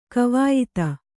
♪ kavāyit